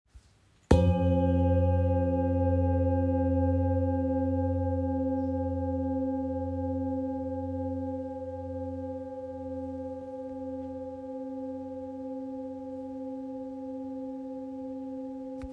Master Series Singing Bowls 30 – 33cm
30cm Mallet
Every Master Series Singing Bowl is made to deliver a powerful, harmonious sound.
With clarity, warmth, and a long-sustaining tone, these bowls create an enveloping resonance that enhances meditation, sound baths, and therapeutic sessions.
A-30cm-mallet.m4a